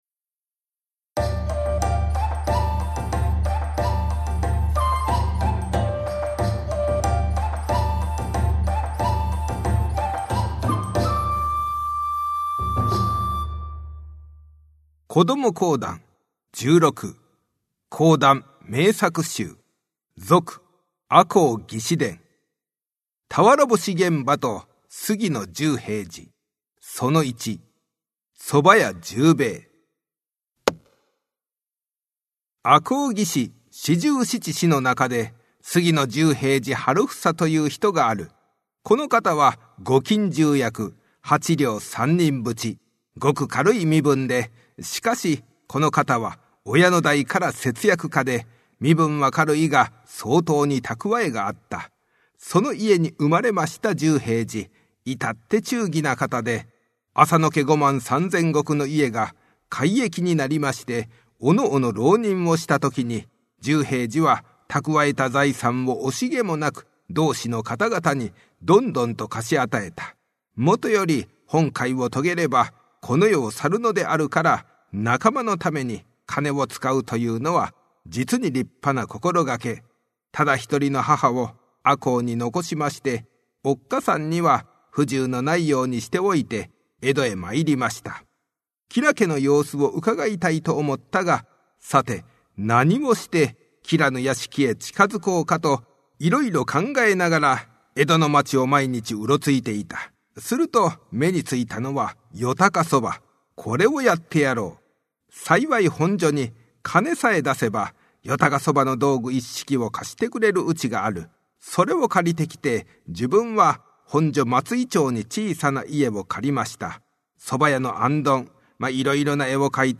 近頃、注目を浴びているのが日本の伝統話芸「講談」。
子供にもわかりやすい講談の入門編。
講談師。